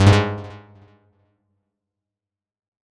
フリー効果音：システム10
フリー効果音｜ジャンル：システム、システム系第10号です！SFっぽい効果音になりました！